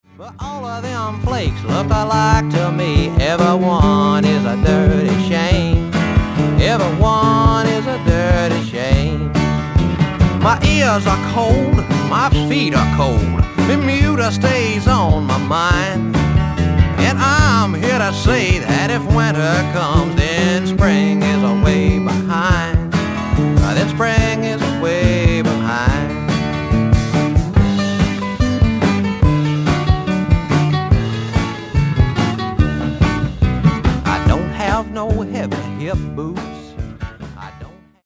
60年代からＮＹ/東海岸を拠点にそれぞれ活動していた、2人のシンガーと3人のインストゥルメンタリストからなる5人組。